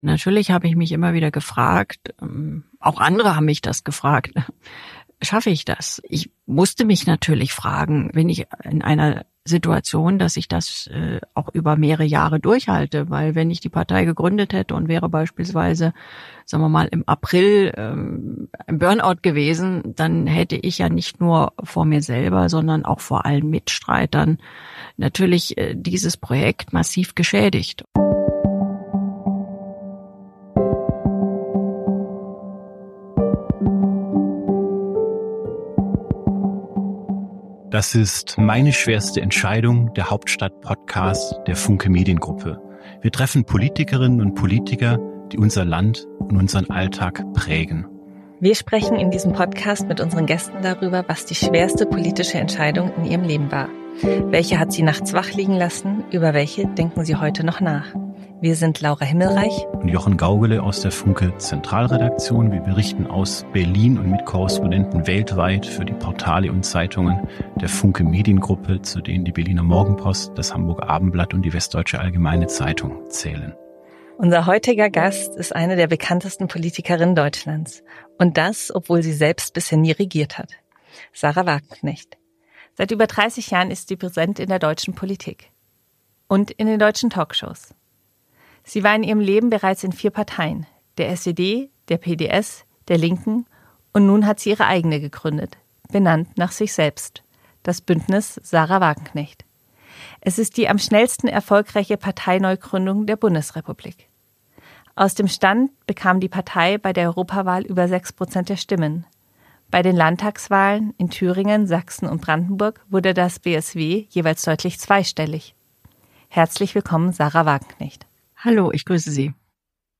Spitzenpolitikerinnen und -politiker im Gespräch